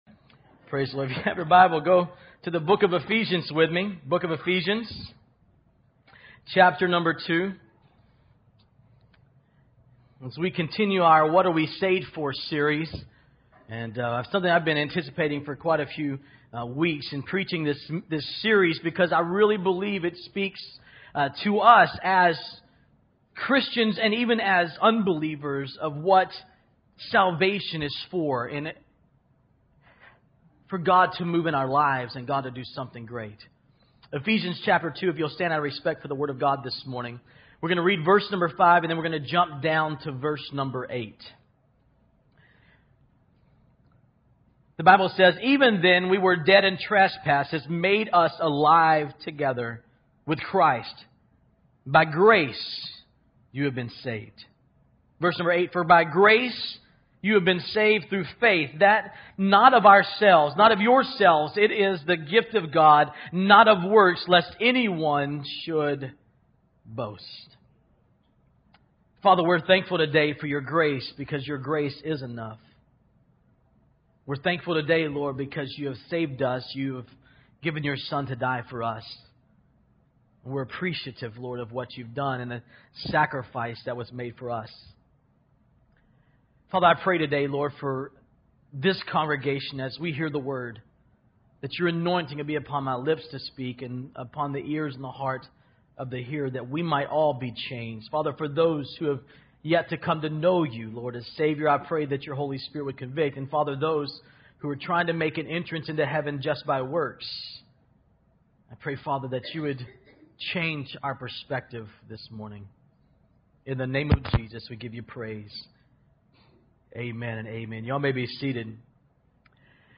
Here is Sunday's message: